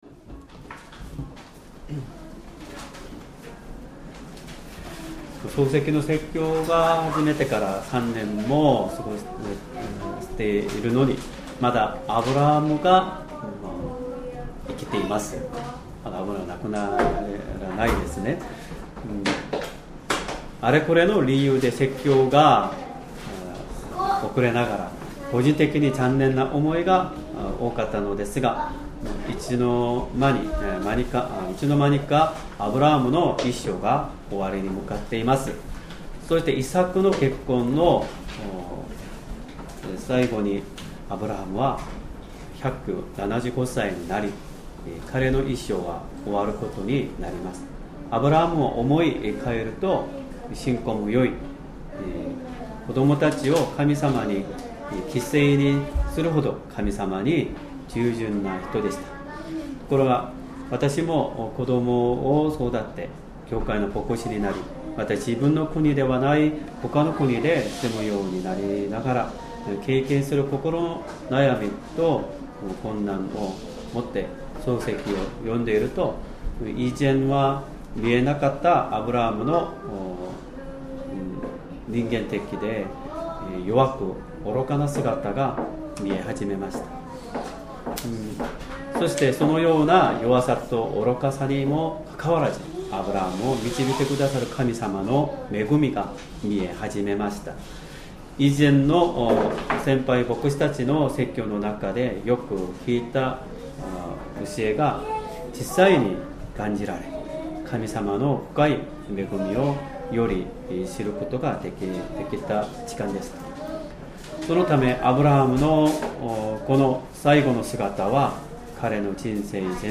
Sermon
2025年8月31日 主日礼拝 説教 「忠実な人のために備えられた道」 聖書 創世記 創世記 24章 1-27節 24:1 アブラハムは年を重ねて、老人になっていた。